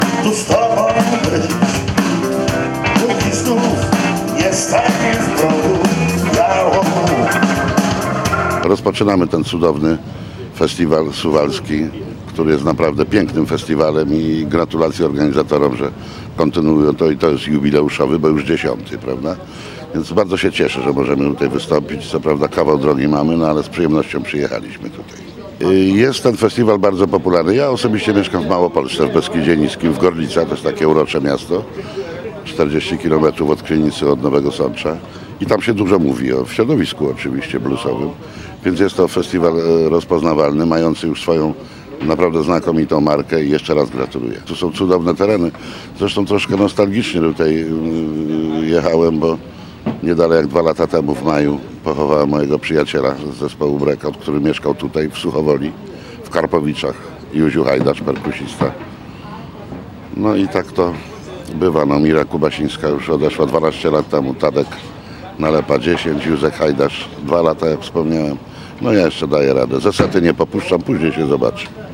Tuż po odsłonięciu tablicy zebrani mogli udać się na pierwsze koncerty, w tym występ Piotr Nowak Band.